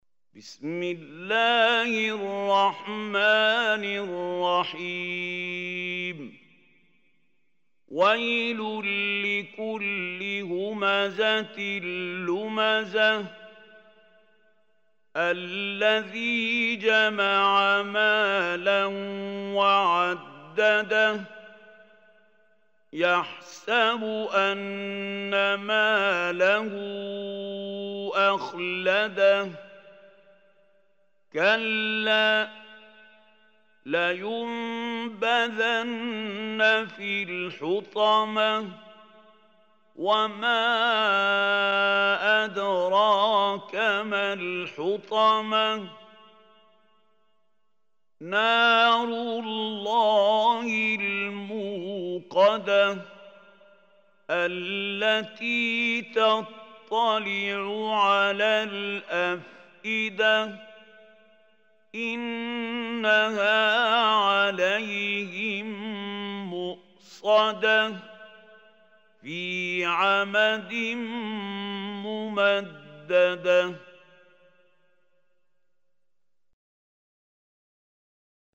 Surah Humazah Recitation By Mahmoud Khalil
Surah al-Humazah is 104 surah of Holy Quran. Listen or play online mp3 tilawat / recitation in Arabic in the beautiful voice of Sheikh Mahmoud Khalil AL Hussary.